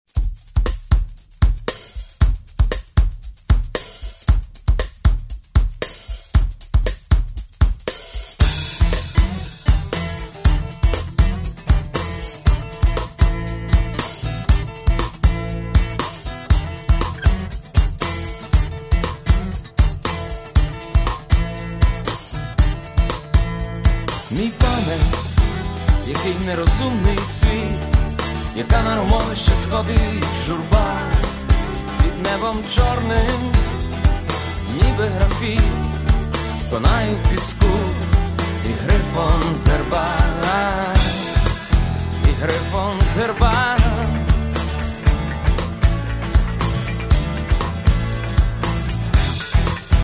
Рок (320)